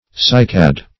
Cycad \Cy"cad\ (s[imac]"k[a^]d), n. (Bot.)